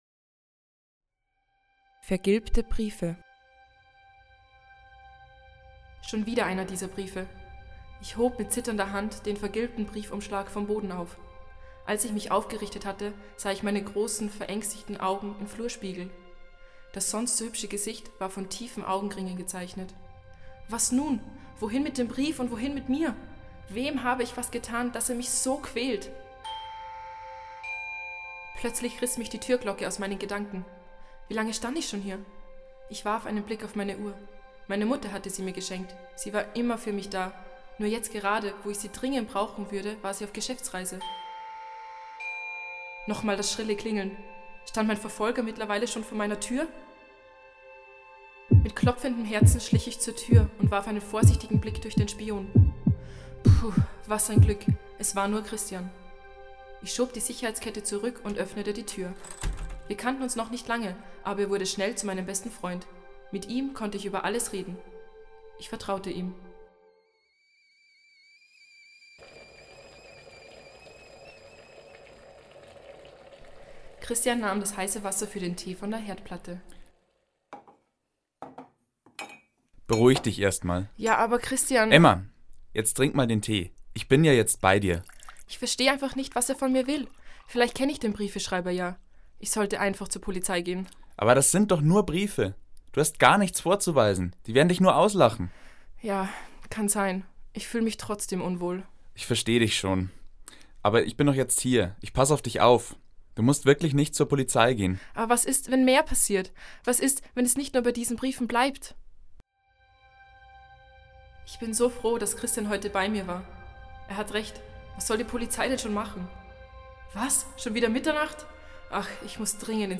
24. Hörspiel
Hoerspiel_VergilbteBriefe_komplett.mp3